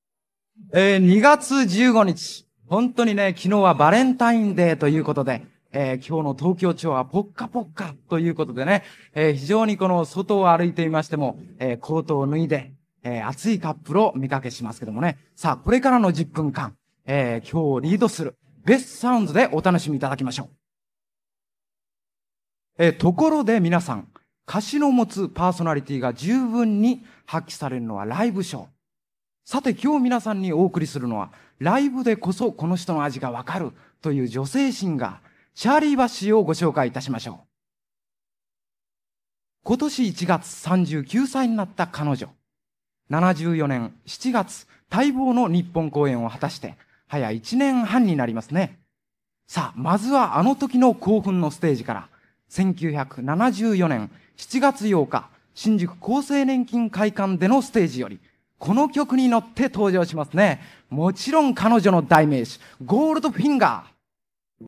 1976年2月、高田馬場BIGBOXで開催されたDJ大会。
▶ DJ音声②（1曲目紹介）
②DJの声-ベストサウンド無し1曲目の紹介まで-2.mp3